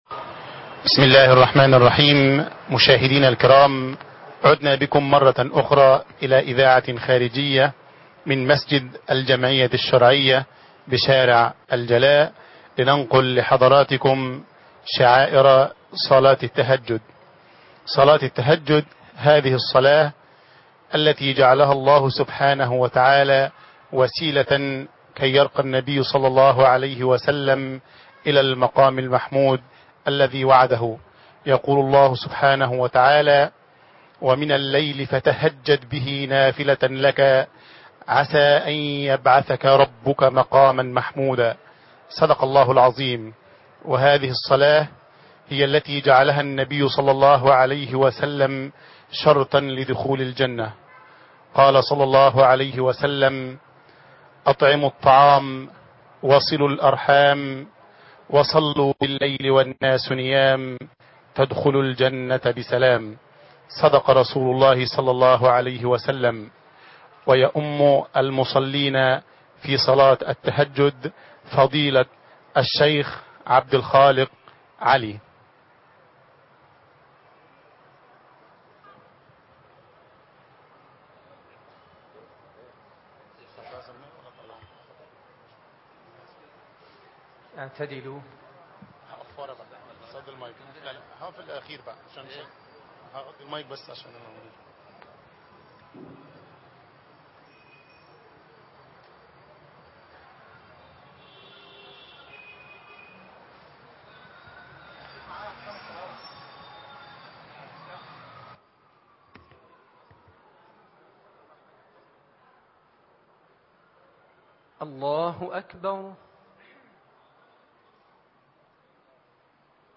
صلاه التهجد من مسجد الجمعيه الشرعيه بالجلاء (28/8/2011) - قسم المنوعات